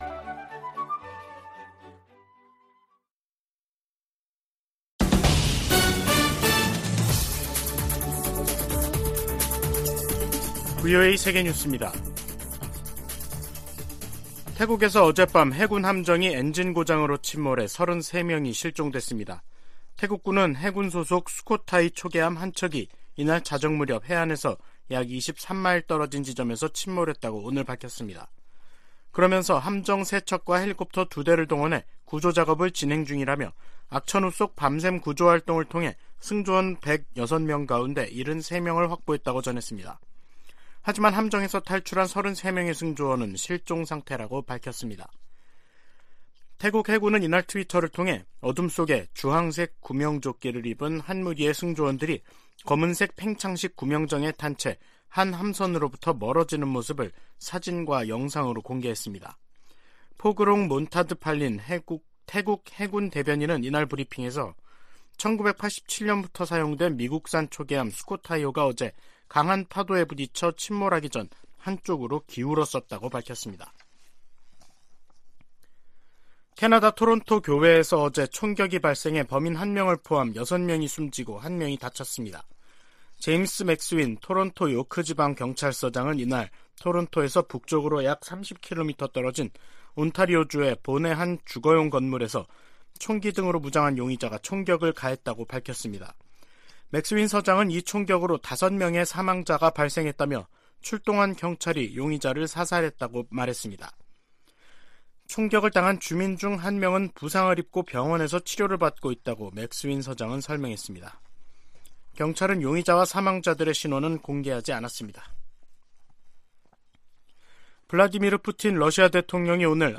VOA 한국어 간판 뉴스 프로그램 '뉴스 투데이', 2022년 12월 19일 2부 방송입니다. 북한은 내년 4월 군 정찰위성 1호기를 준비하겠다고 밝혔지만 전문가들은 북한의 기술 수준에 의문을 제기하고 있습니다. 미국 국무부는 북한이 고출력 고체 엔진실험을 감행한 데 대해 국제사회가 북한에 책임을 묻는 일을 도와야 한다며 단합된 대응을 강조했습니다.